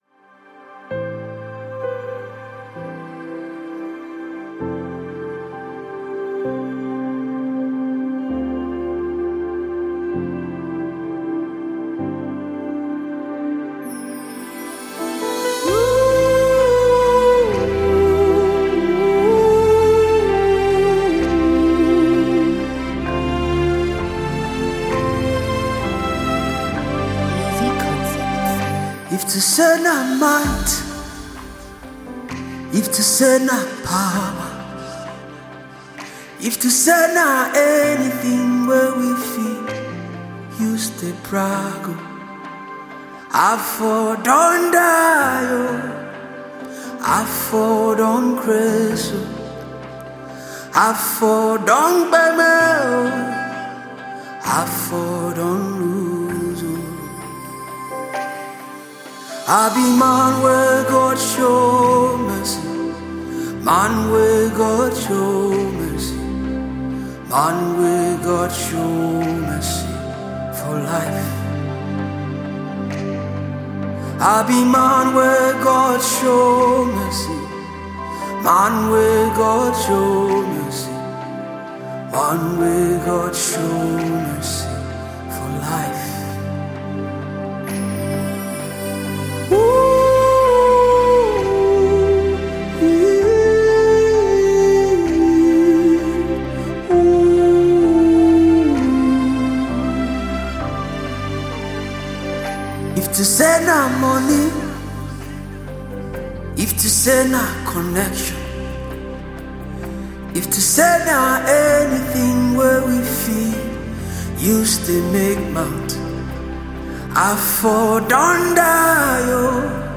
October 14, 2024 admin Gospel, Music 0